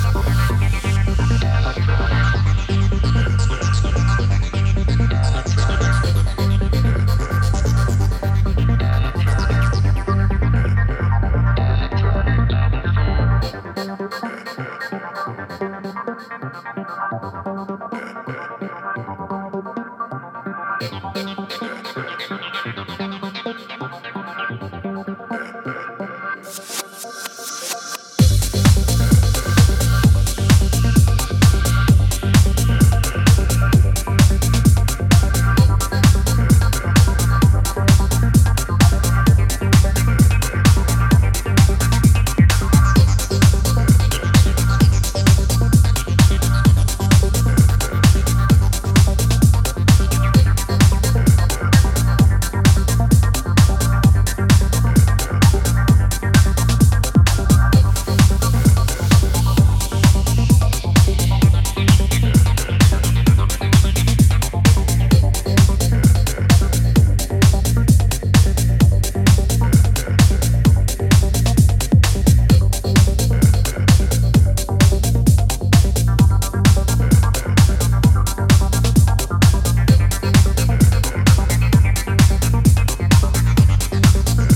dance music